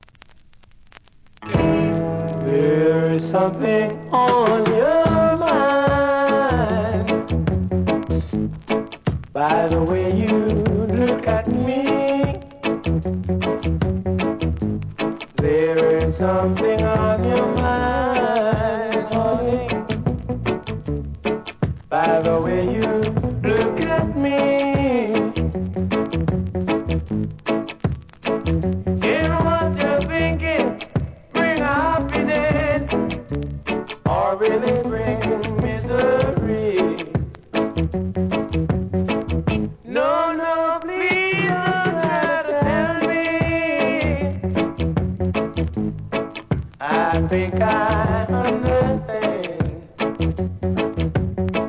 REGGAE
イントロから素晴らしいヴォーカルに加えてSAXフォーンがムードを盛り上げます!